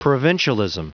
Prononciation du mot provincialism en anglais (fichier audio)
Prononciation du mot : provincialism